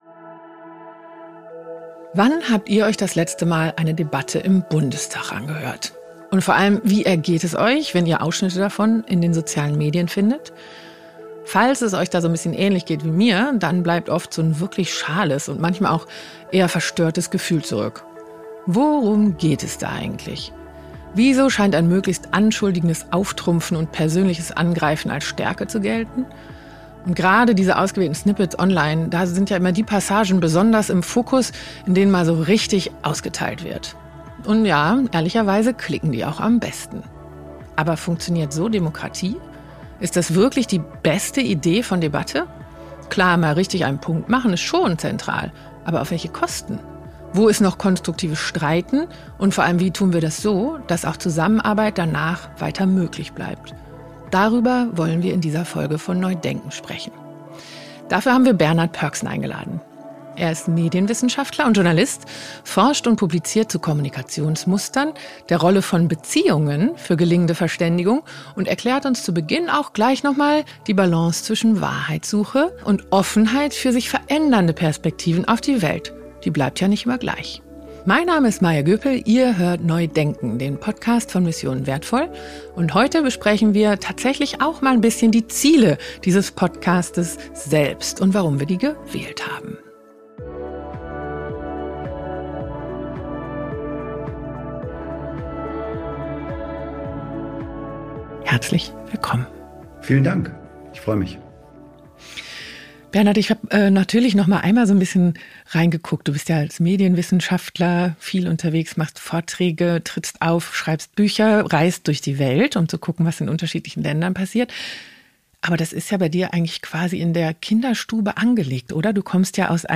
In dieser Folge von NEU DENKEN spricht Maja Göpel mit Bernhard Pörksen über Debattenkultur, über Wahrheitssuche und Offenheit – und darüber, welche Ziele sich dieser Podcast selbst setzt, wenn es um respektvolle, demokratische Verständigung geht.